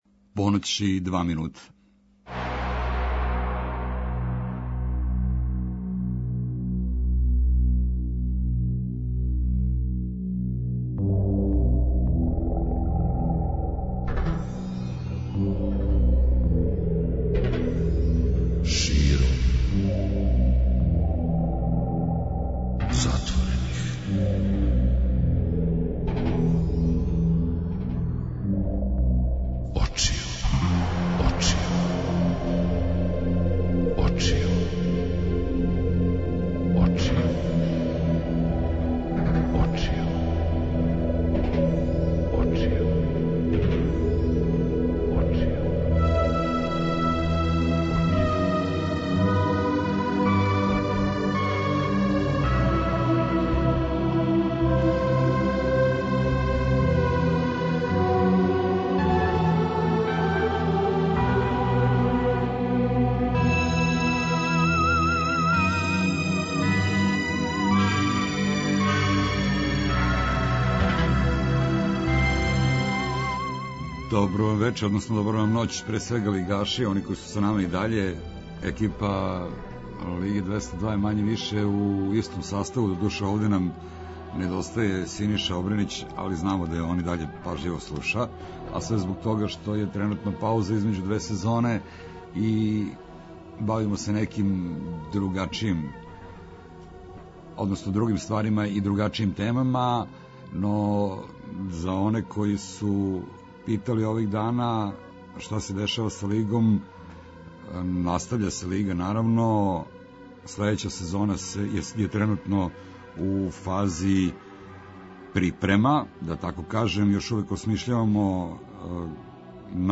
Ове ноћи очекује вас и један ексклузивни интервју.